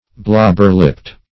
Search Result for " blobber-lipped" : The Collaborative International Dictionary of English v.0.48: Blobber-lipped \Blob"ber-lipped`\ (-l[i^]pt`), a. Having thick lips.